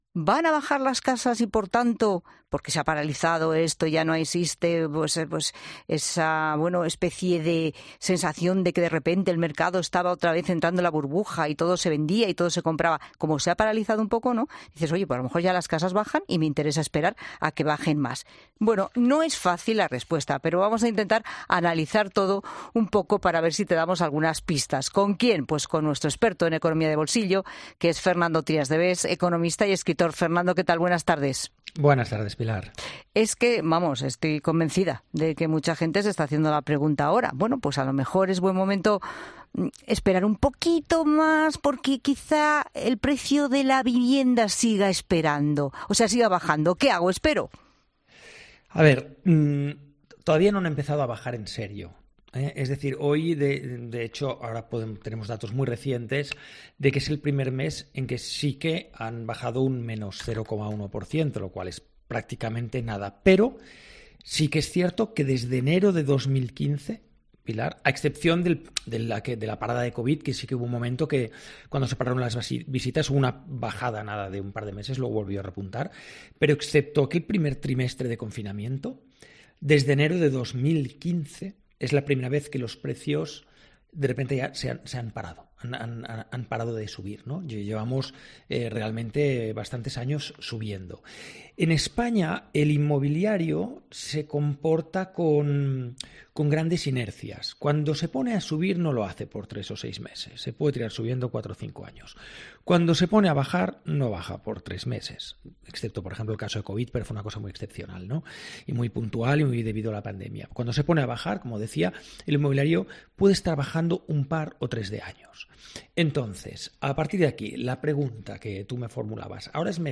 El economista Fernando Trías de Bes aclara en La Tarde si es el momento de comprar o vender tu casa tras el enfriamiento de los precios en el último...